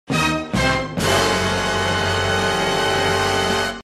(Dramatic Music!)
DUN-DUN-DUUUUN-Dramatic-Sound-Effect.mp3